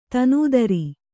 ♪ tanūdari